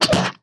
hit-v4.ogg